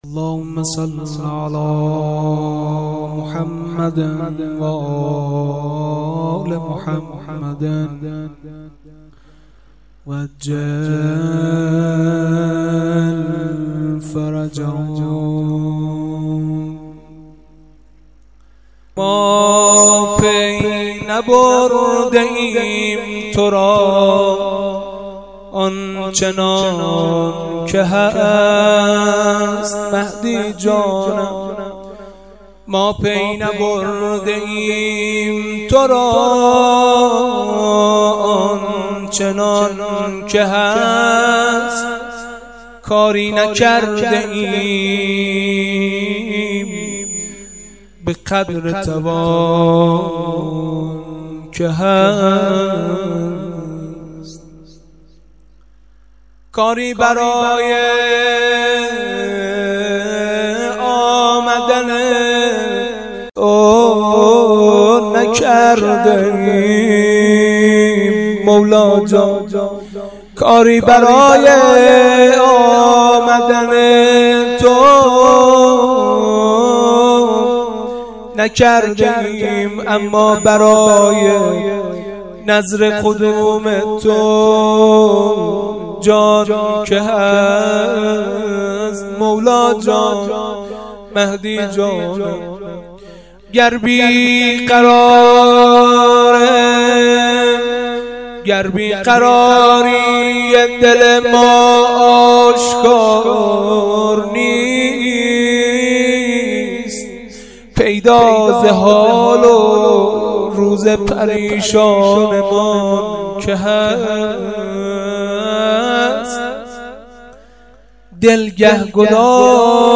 جلسه زیارت عاشورای هفتگی هیئت شهدای گمنام93/06/10